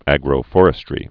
(ăgrō-fôrĭ-strē, -fŏr-)